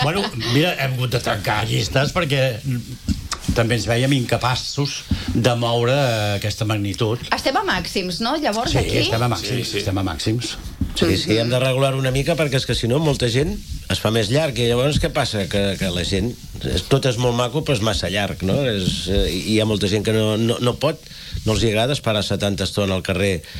Segons ha explicat la comissió organitzadora en una entrevista aquest dimecres al matinal de RCT, enguany s’han vist obligats a tancar les inscripcions abans del previst a causa de l’elevada demanda.